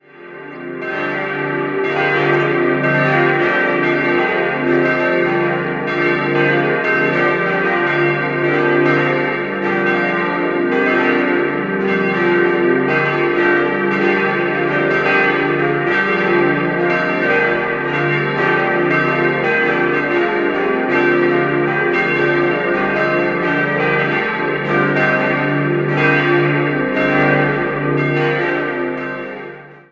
Dies änderte sich nach dem Zweiten Weltkrieg, so dass von 1963 bis 1965 die neue Heilig-Geist-Kirche samt Pfarrzentrum errichtet wurde. 5-stimmiges Geläute: d'-f'-g'-a'-c'' Alle Glocken stammen aus der Gießerei Heidelberg aus dem Jahr 1980.